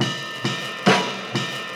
FOREIGNTECK_Way Back_Drum_Loop_136bpm.wav